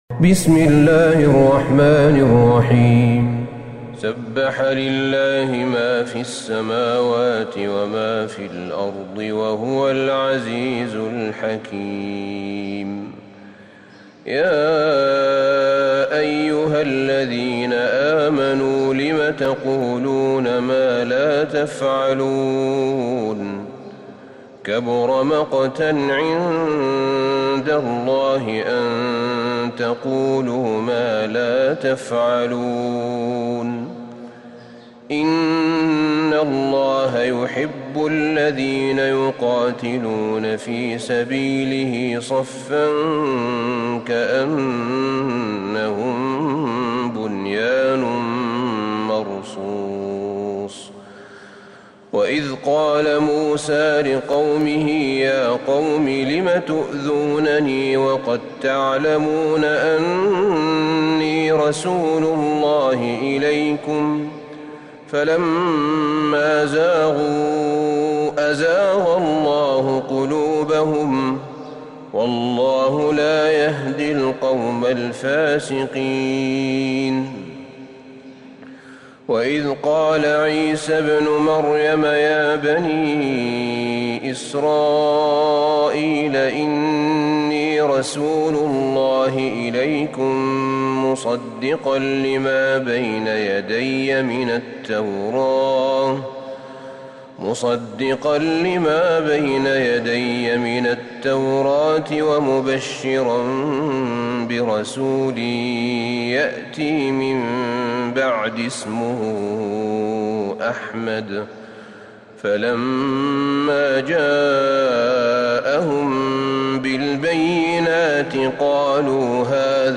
سورة الصف Surat AsSaff > مصحف الشيخ أحمد بن طالب بن حميد من الحرم النبوي > المصحف - تلاوات الحرمين